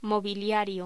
Locución: Mobiliario
voz